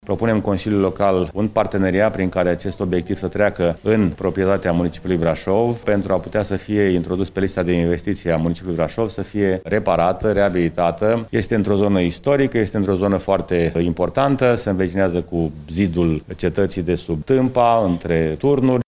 Primarul municipiului, George Scripcaru: